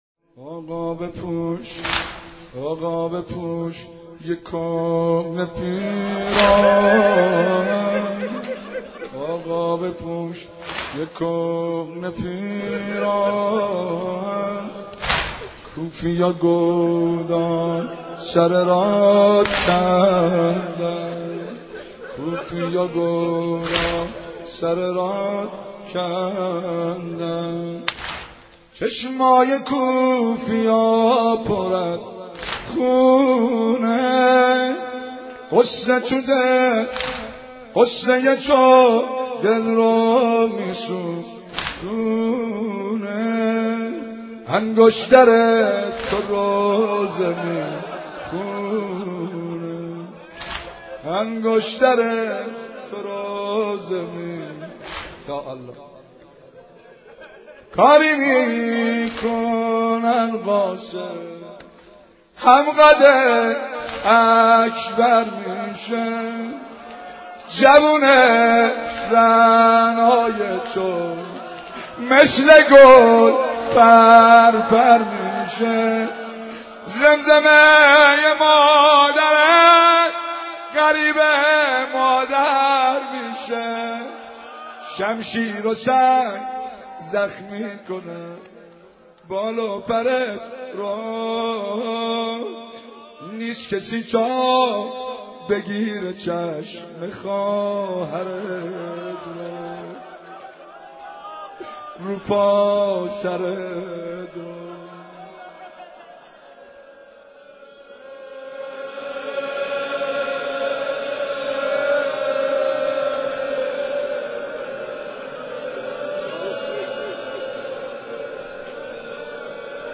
متن سینه زنی واحد شهادت امام حسین